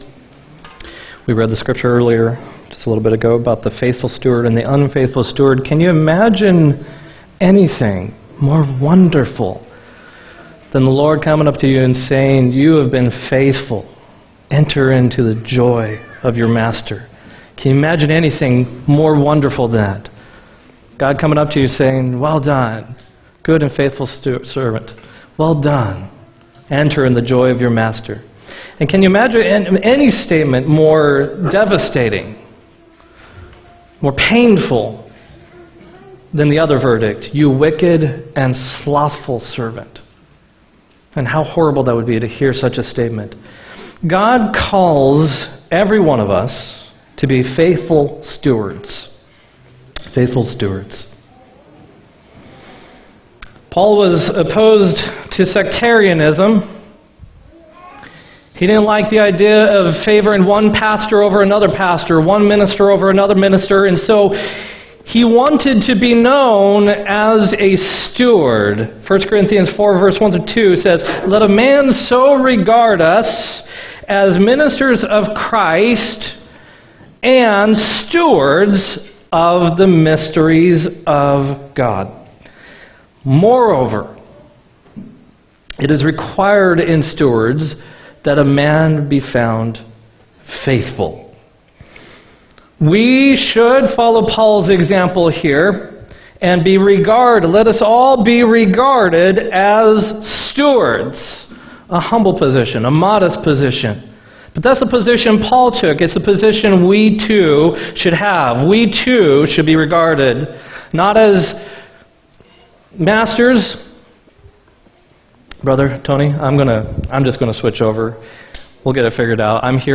11-10-18 sermon